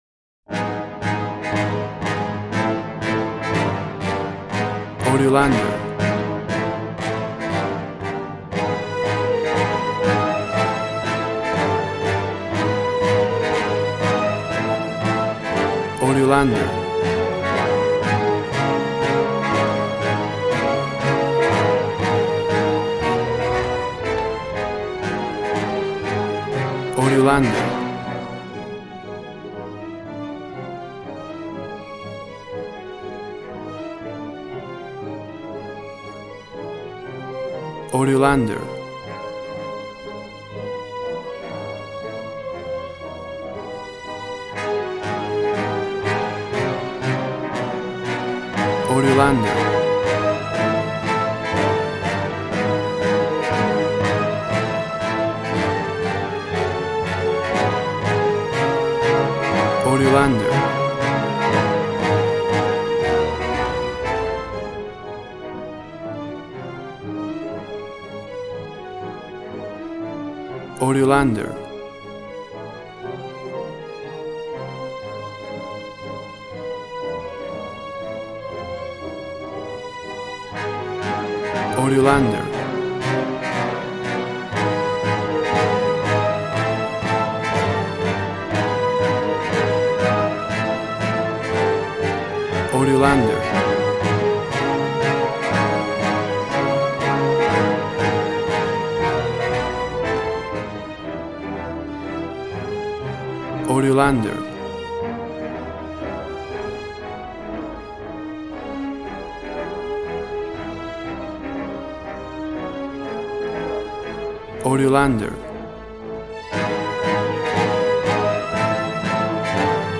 Tempo (BPM): 120